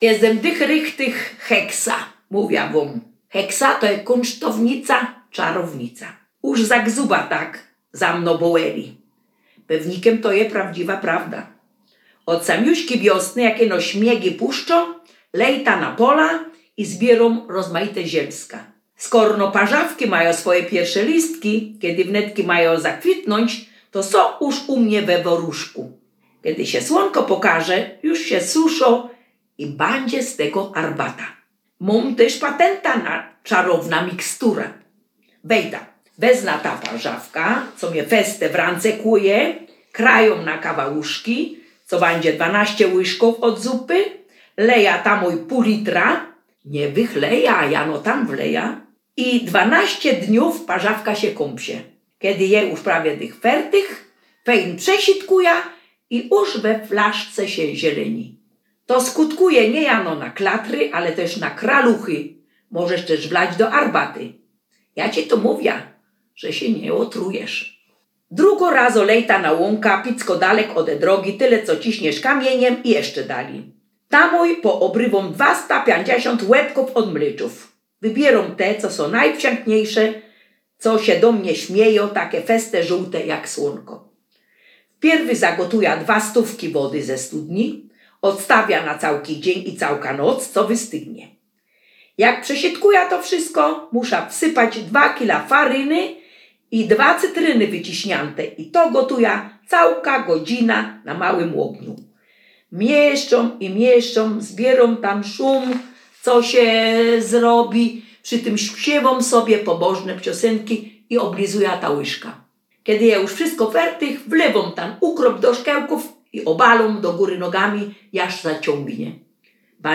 Opowieść czarownicy – kunsztownicy.